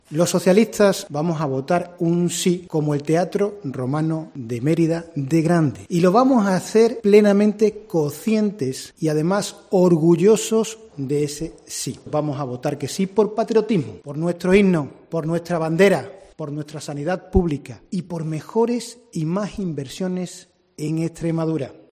Escucha a los diputados del PSOE extremeños, Juan Antonio González y Begoña García Bernal